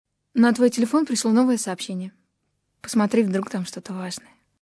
/64kbps) Описание: Самый приятный голос девушки на входящее собщение.
Dlya_sms_golos_devuwki_.mp3